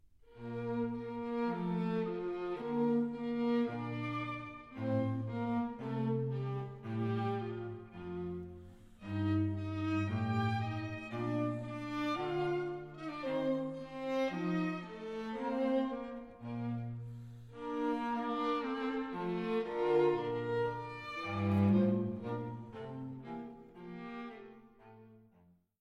für Violine, Viola und Violoncello: Andante